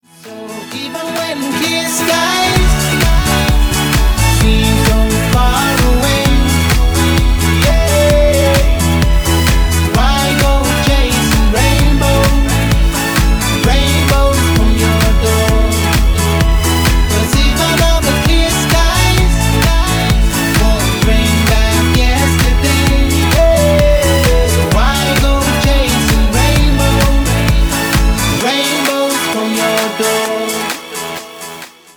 позитивные
мужской вокал
веселые
dance
добрые
house